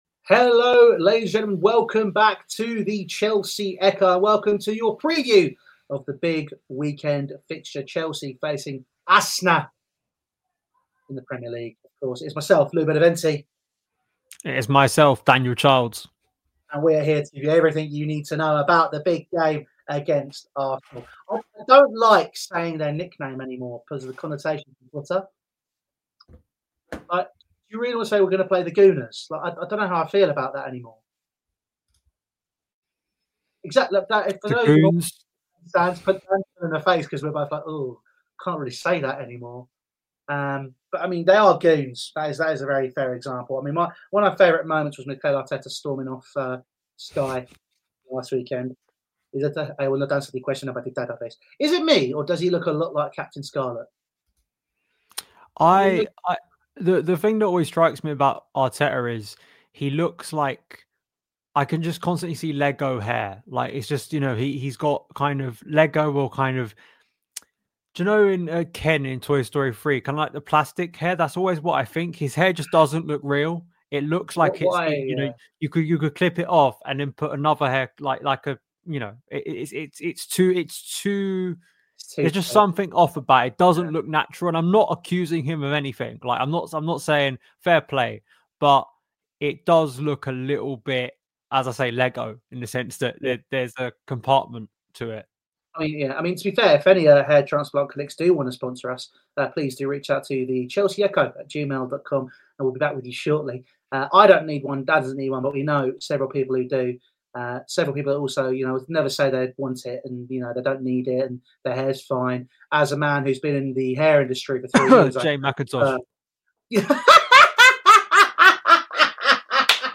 *Apologies for the audio issues!* Share Facebook X Subscribe Next Enzo Maresca's Plan A and Plan A | Arsenal 1-0 Chelsea | Voicenote Review Top episodes Cole Palmer: The BEST in the world?